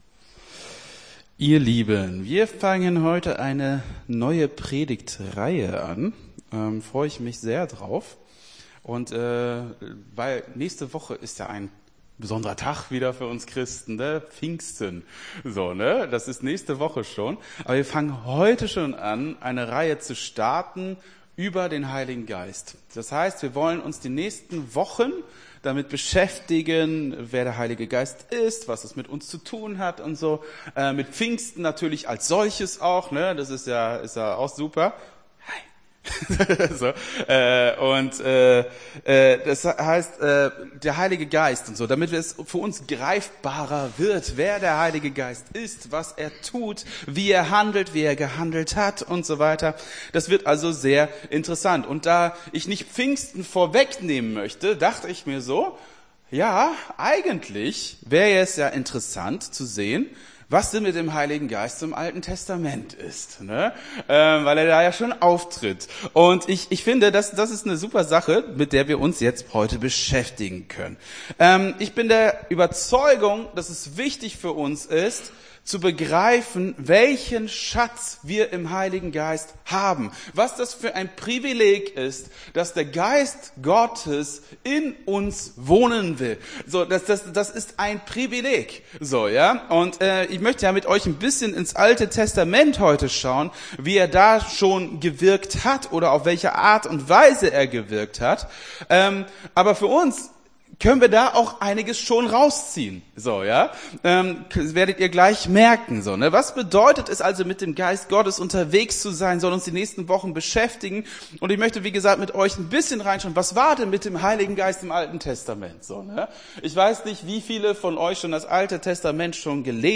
Gottesdienst 21.05.23 - FCG Hagen